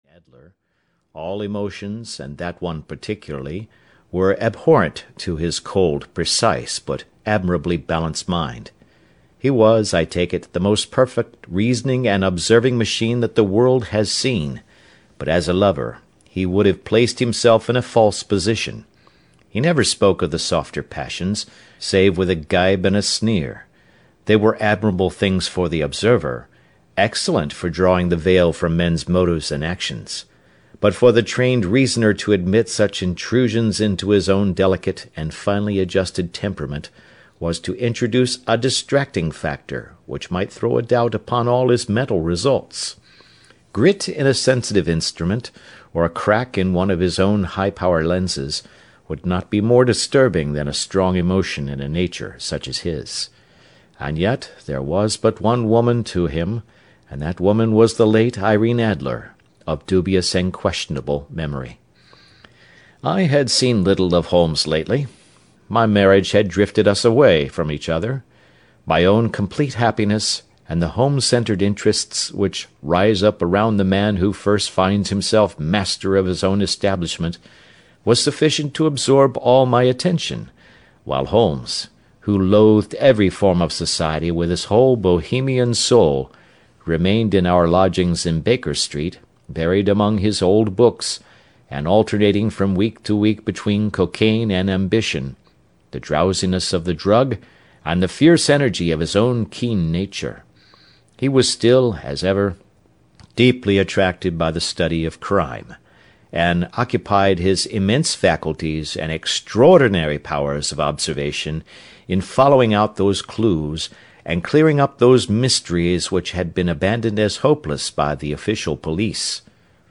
The Adventures of Sherlock Holmes (EN) audiokniha
Ukázka z knihy